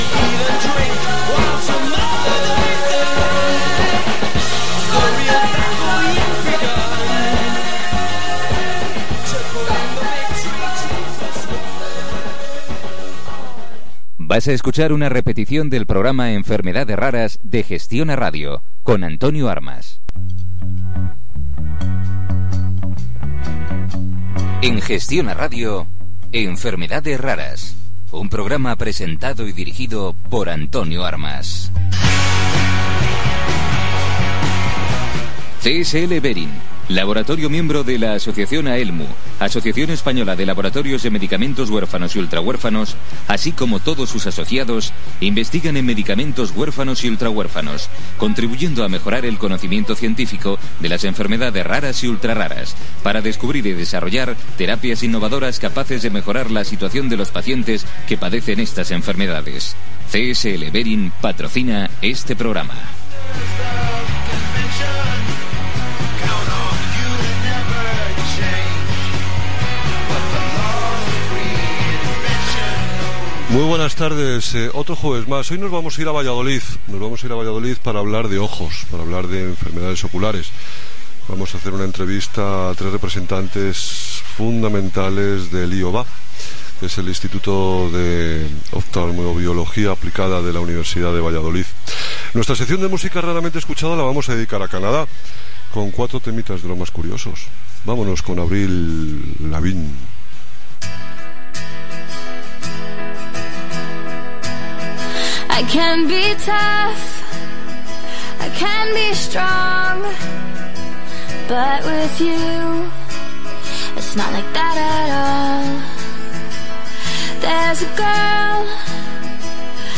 El programa de radio ‘Enfermedades Raras’ del 29 de octubre de 2015